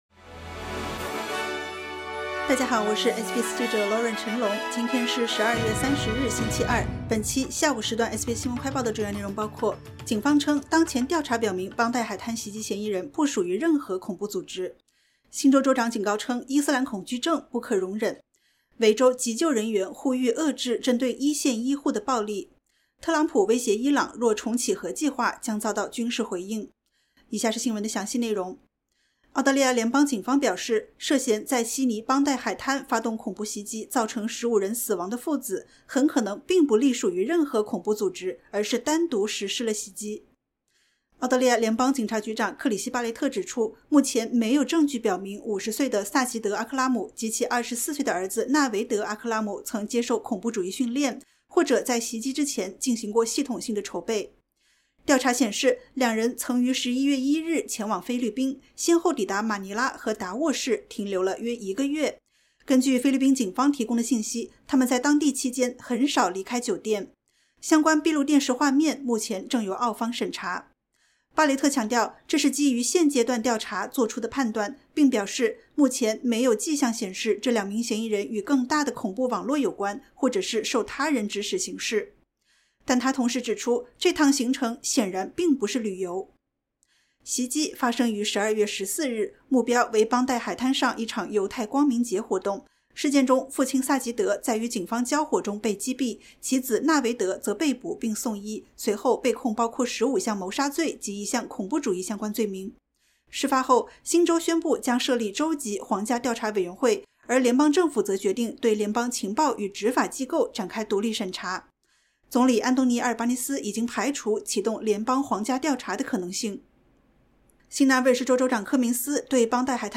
SBS 新闻快报